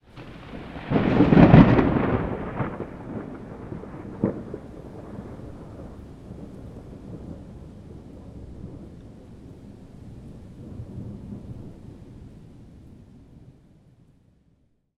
thunder_2.ogg